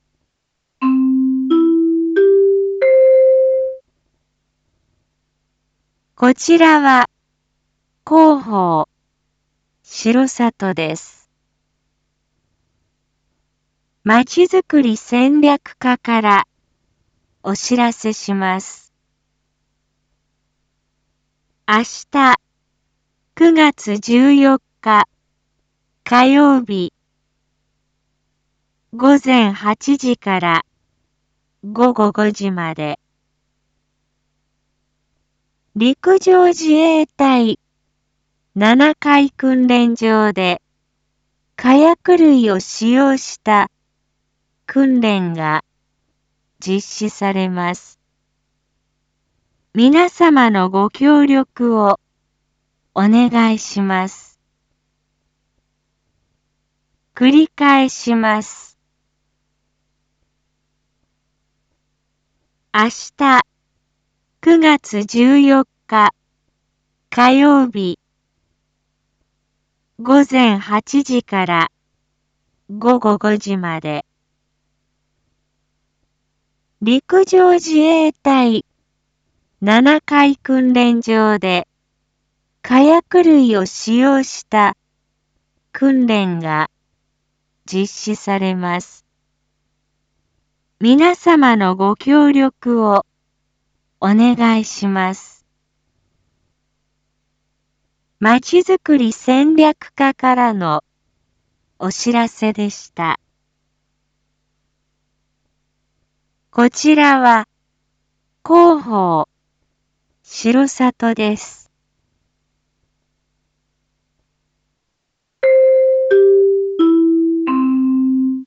Back Home 一般放送情報 音声放送 再生 一般放送情報 登録日時：2021-09-13 19:06:59 タイトル：R3.9.13 19時放送(七会地区のみ放送) インフォメーション：こちらは広報しろさとです。